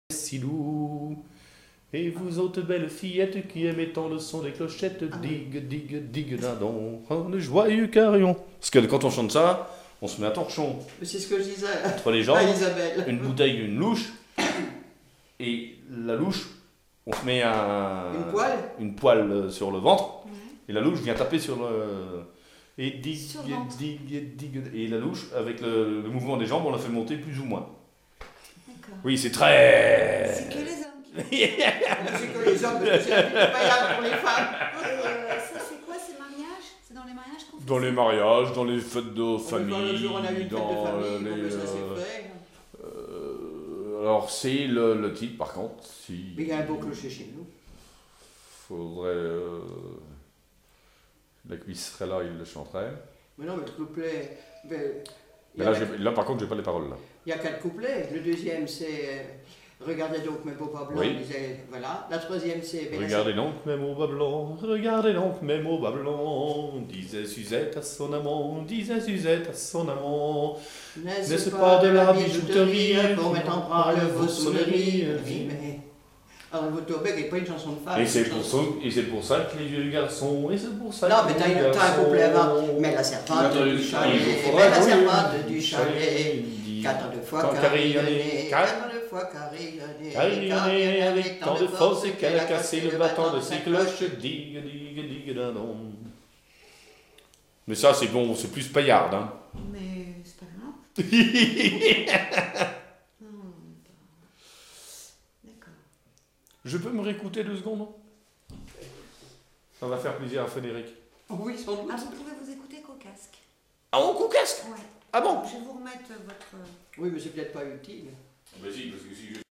Mémoires et Patrimoines vivants - RaddO est une base de données d'archives iconographiques et sonores.
Chansons traditionnelles et populaires
Pièce musicale inédite